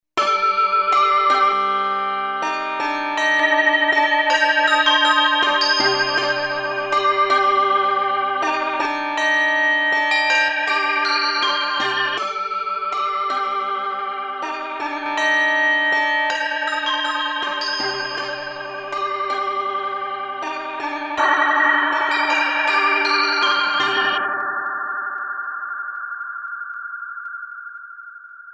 Modulation +echo bell
modulation-bell.mp3